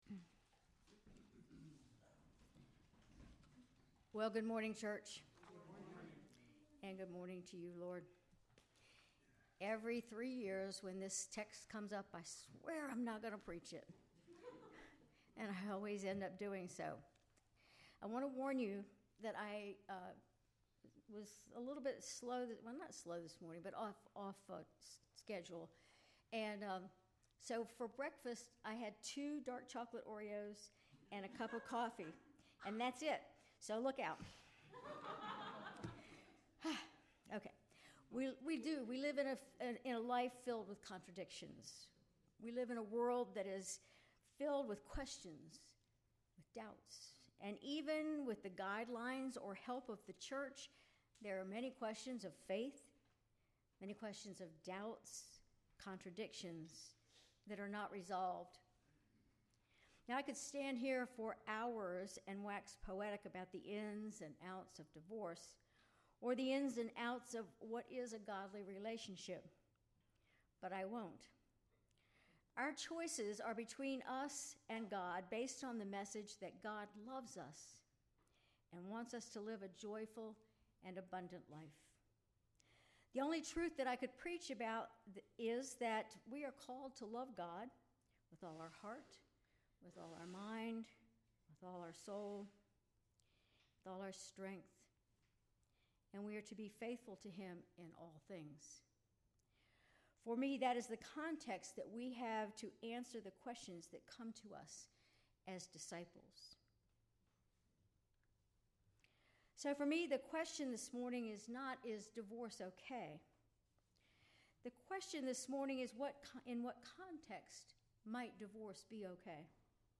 Sermon October 6, 2024
Sermon_October_6_2024.mp3